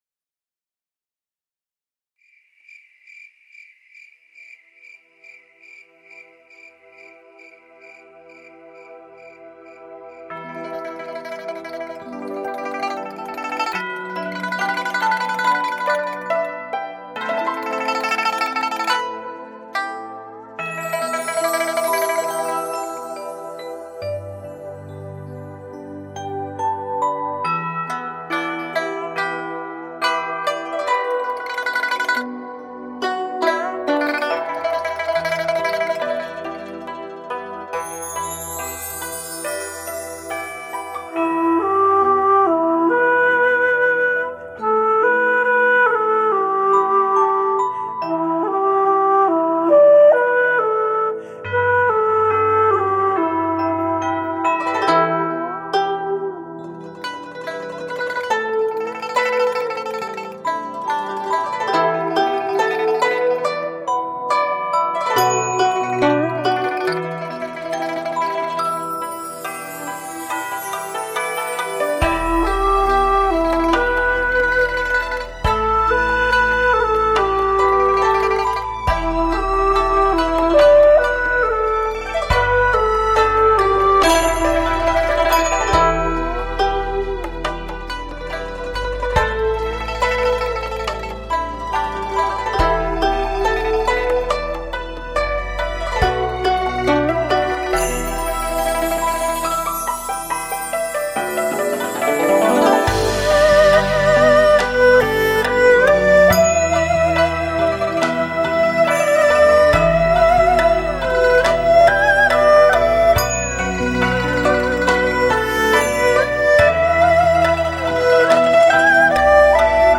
佛乐作品专辑
采用最新DSD录音技术、音质细腻、音效逼真
二胡
笛子/箫
扬琴、琵琶呼应着被箫声带入了一片略带伤感的境界，
忧伤的二胡流淌着奏出人世间的风风雨雨和酸甜苦辣。